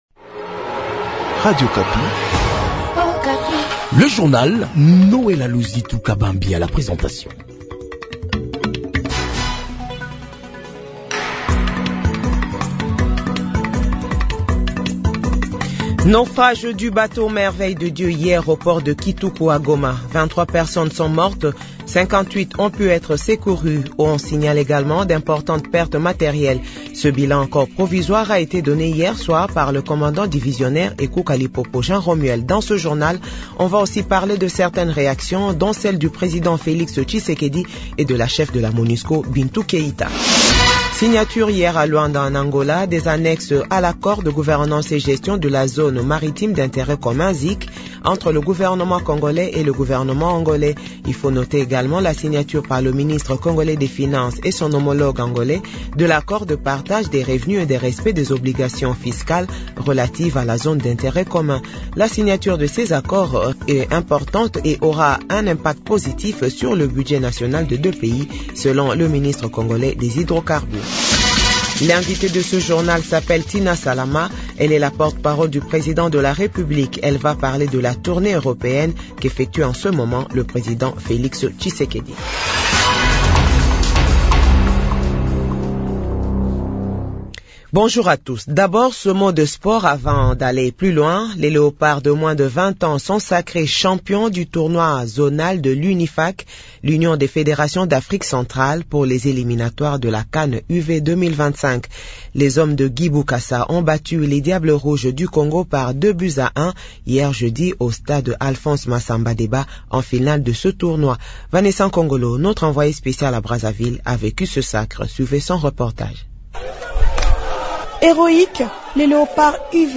JOURNAL FRANCAIS DE 6H00 - 7H00